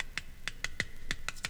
CLICKS.WAV